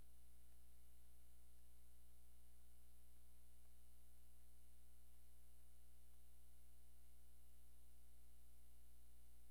DOG PADDLE-L.wav